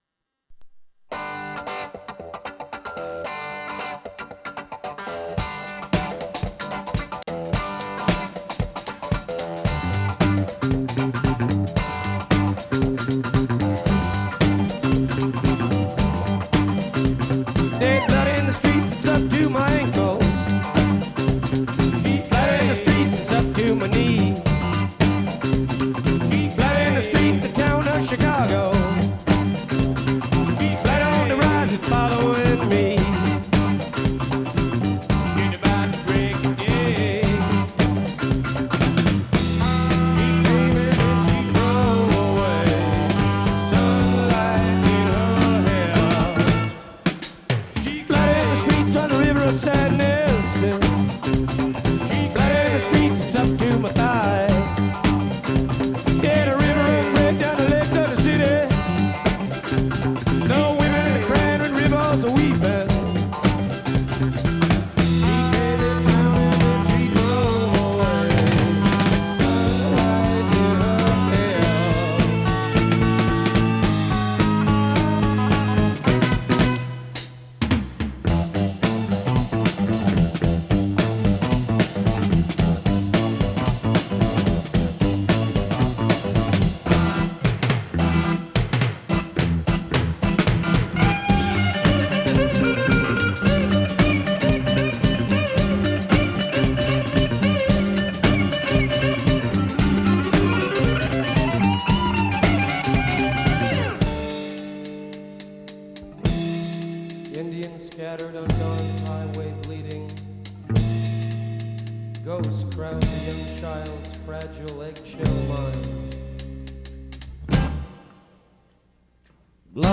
Intervistiamo una Celebrità che ha partecipato a tutto il weekend dedicato all’incoronazione di Re Carlo.